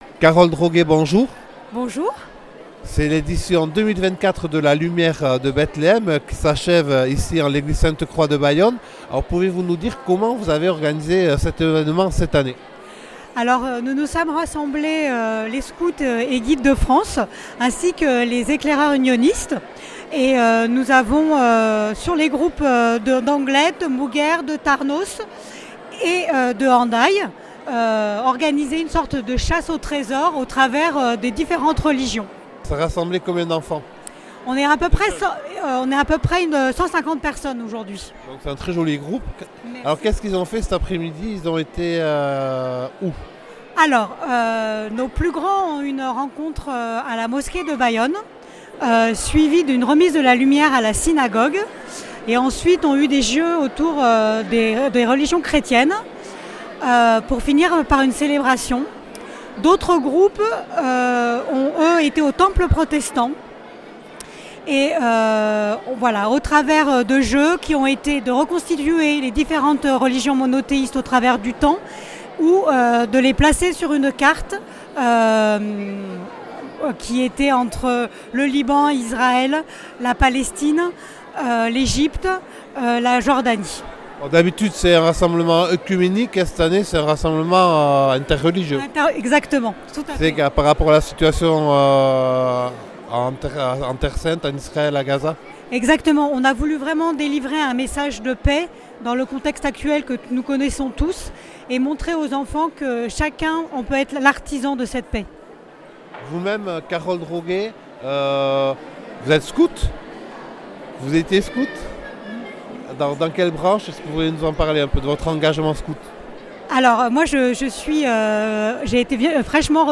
C’est au cours de la semaine de prière pour l’unité des chrétiens que la lumière de la paix de Bethléem est passée à Bayonne, accueillie par les scouts catholiques et protestants. Une marche a débuté à 14h de l’église Saint Esprit, et à 16h30 a eu lieu une belle célébration œcuménique à l’église Sainte-Croix de Bayonne, avec une prière pour la paix.
Interviews et reportages